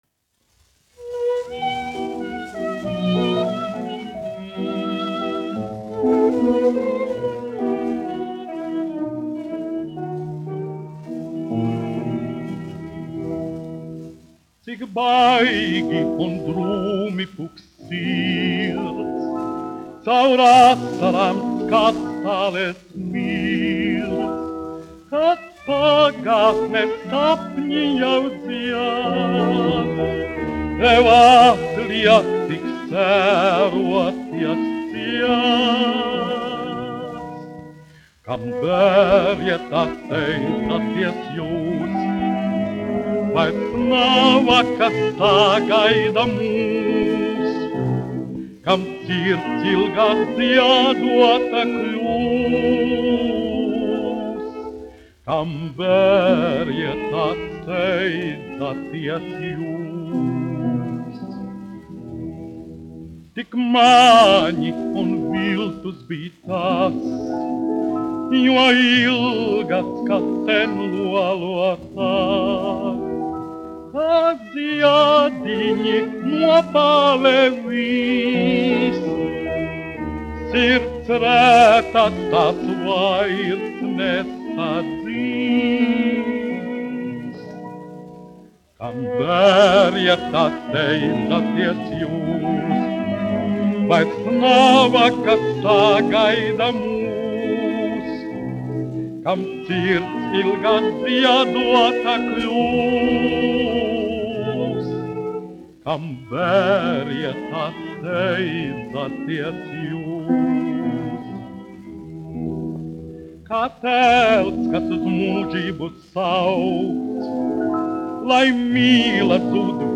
1 skpl. : analogs, 78 apgr/min, mono ; 25 cm
Romances (mūzika)
Latvijas vēsturiskie šellaka skaņuplašu ieraksti (Kolekcija)